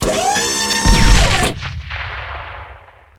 cannon.ogg